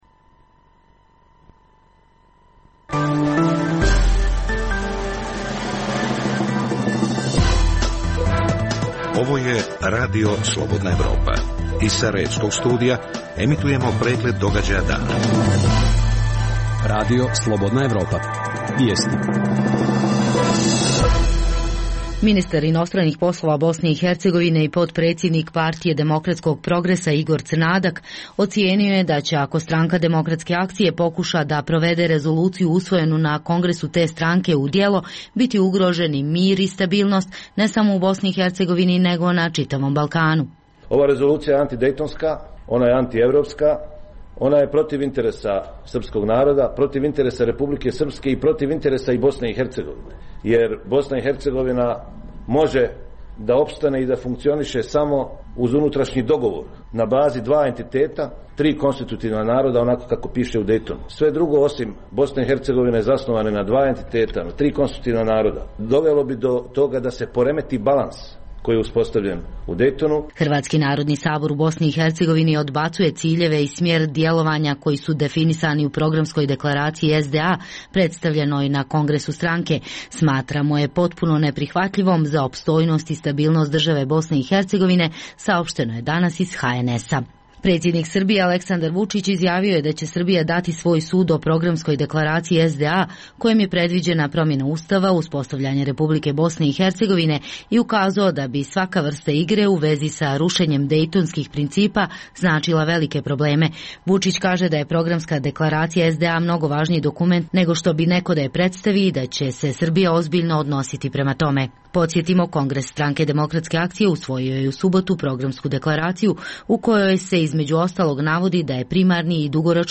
Emisija sadrži vijesti, analize, reportaže i druge sadržaje o procesu integracije BiH u Evropsku uniju i NATO.